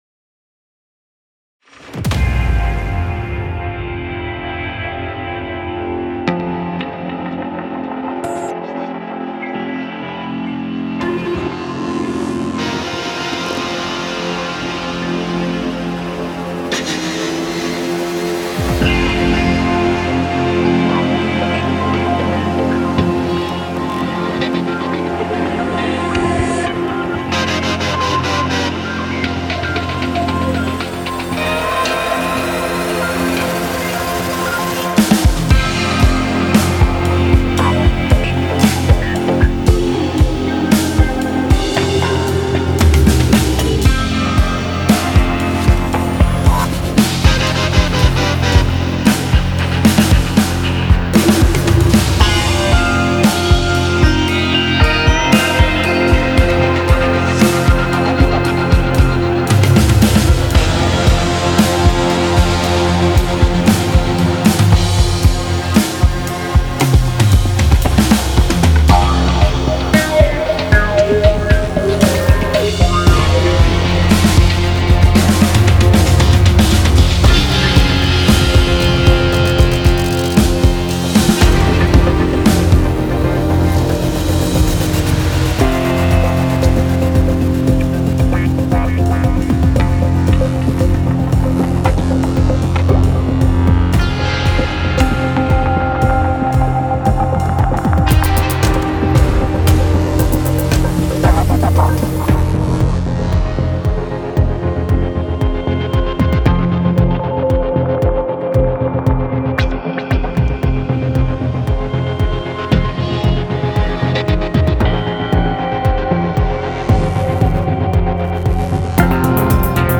Genre:Cinematic
本作のサウンドは多数のエフェクトチェーンを駆使して高度に加工されており、パック全体に独自のキャラクターを与えています。
111 Dark Guitar Loops
72 Guitar Atmospheres